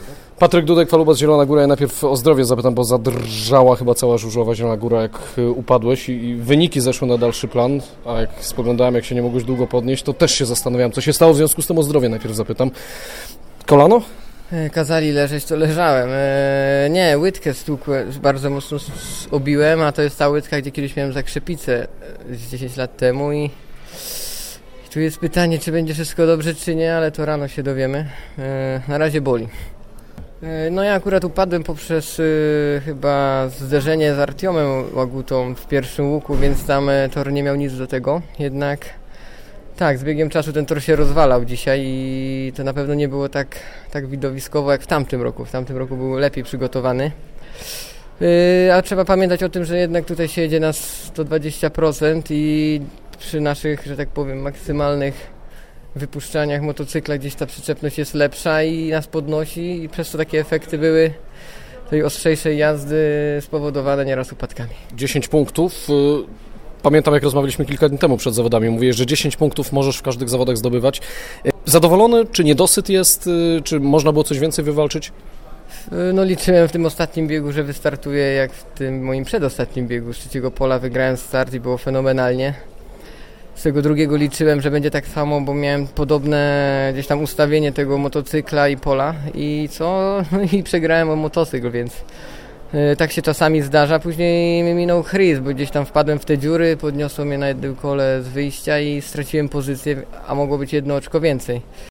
Posłuchajcie rozmowy z Patrykiem Dudkiem tuż po zawodach: